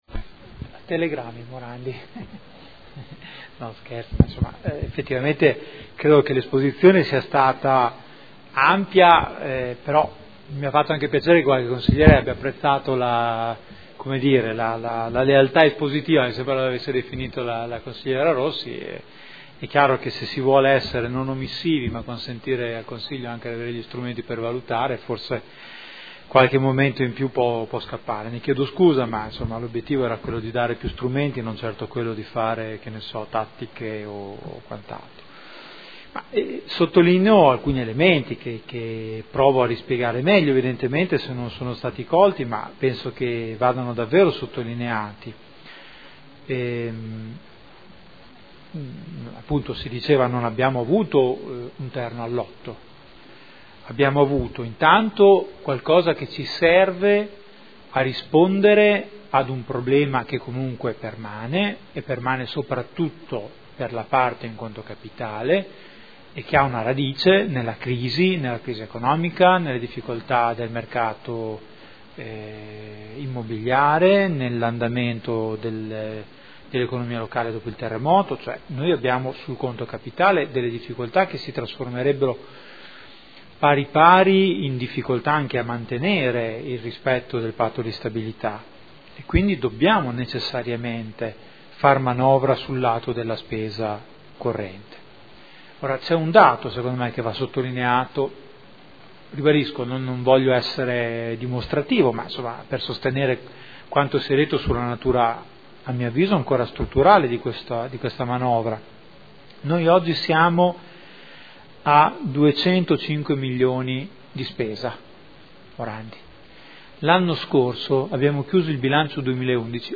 Giuseppe Boschini — Sito Audio Consiglio Comunale